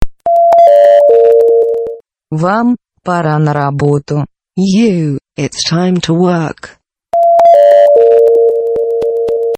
Категория: Напоминание